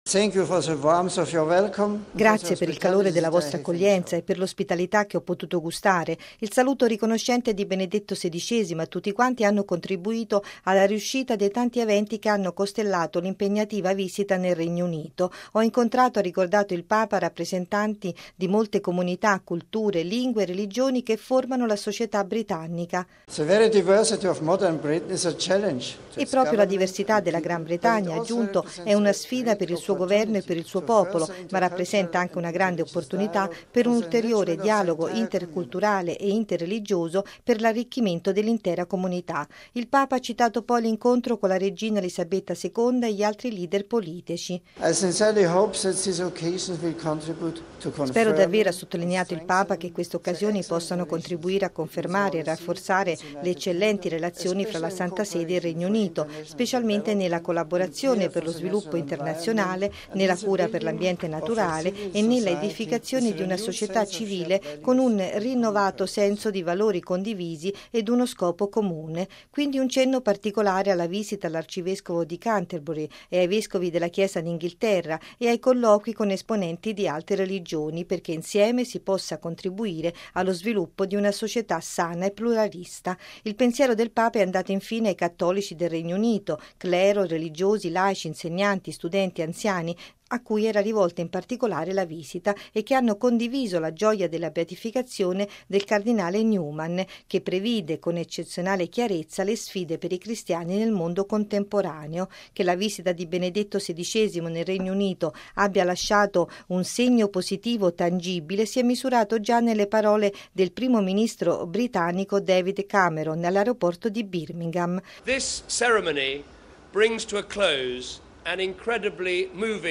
◊   “La diversità della Gran Bretagna moderna”: una “sfida” ma anche “una grande opportunità”: così il Papa nel discorso di congedo all’aeroporto di Birmingham, al termine del suo XVII viaggio apostolico di quattro giorni nel Regno Unito, la prima visita di Stato di un Pontefice in questo Paese.